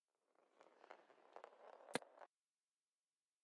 滚珠撞到鞋子.ogg